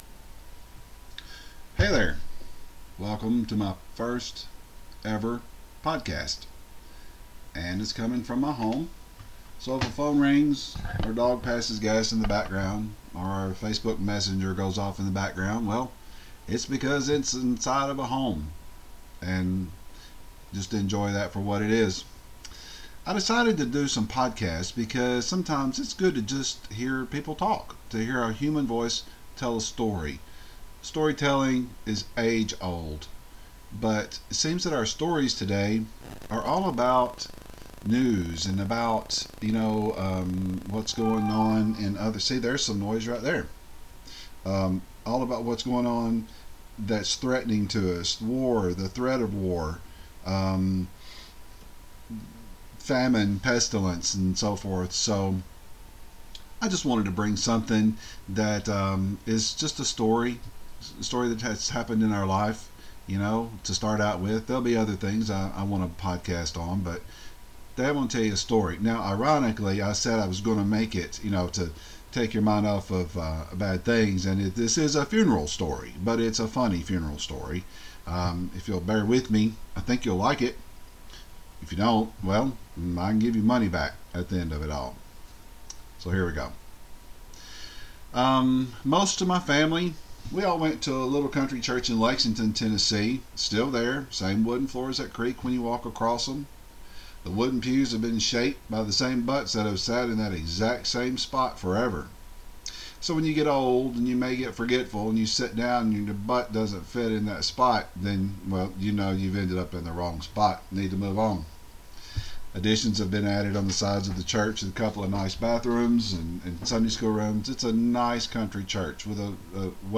Or you can grab a cup of coffee and savor my southern drawl.